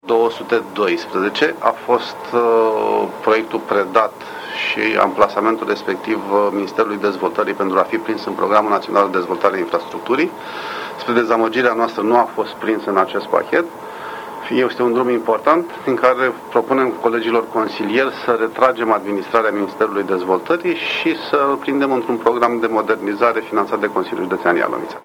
audio IOAN MARTIN